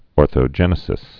(ôrthō-jĕnĭ-sĭs)